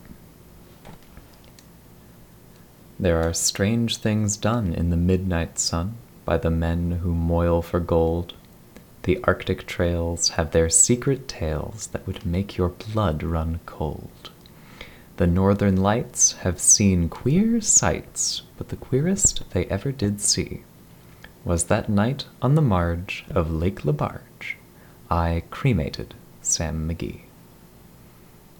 ribbon mic – kHz
i didn't follow horowitz & hill's proposition, and used a transformer to make the requisite low noise floor easier to achieve. most likely this only somewhat helped, because my apartment has no earth ground and i suspect much of the noise i'm seeing is magnetic interference from my other equipment, but whatever.
informally: the damn thing worked! and sounded good, too: comments from folks i know centered around “it sounds like i'm in the room with you.” it also picked up my relatively deep voice well, which i'm really happy about — ribbon tensioning is apparently an art and i am an engineer, so i'm glad i got it close to right after a few tries.